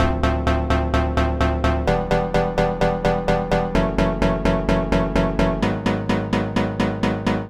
Но даже если играть исключительно пресетами, совершенно ничего в них не меняя - без round robin сэмплы "пулемётят".